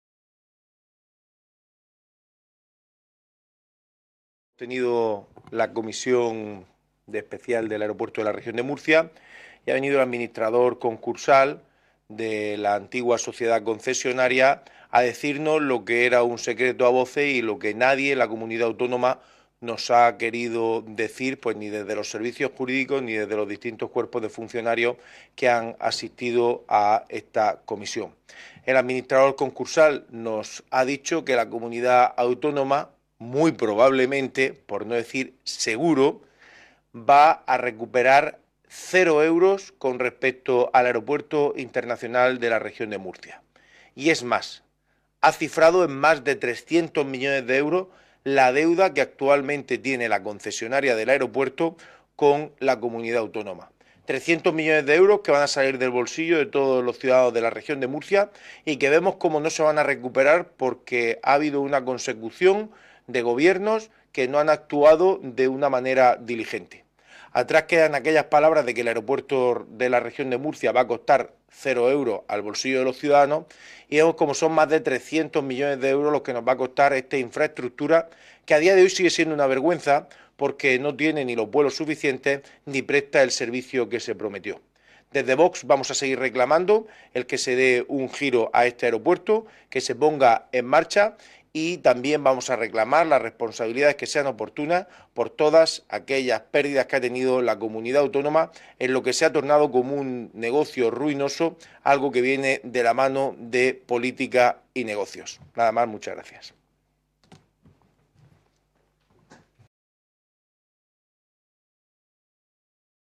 Ruedas de prensa tras la Comisión Especial de Investigación sobre la liquidación fallida por daños y perjuicios en la construcción y explotación del Aeropuerto Internacional de la Región de Murcia